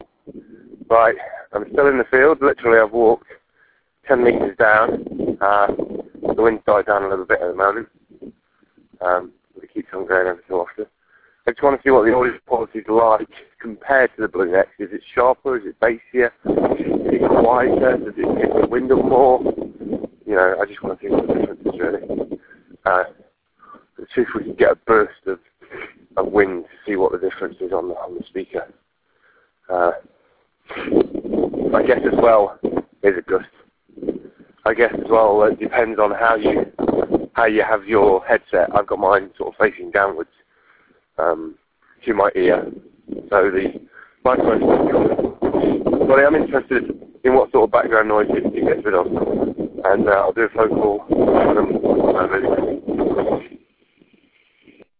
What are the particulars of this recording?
b2 bluenext testing | the iphone in a very windy field